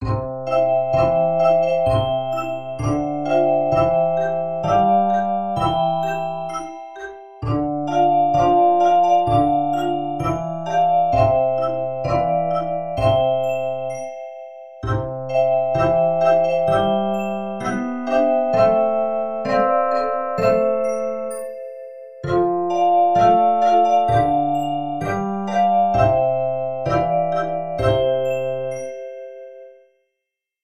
Arreglo Orff-mp3
Senzenina - Solo + 3 voces
Binaria, 2-3 voces
Pulso, Figuras rítmicas, Ostinato
5 notas, Diatónica, Ostinato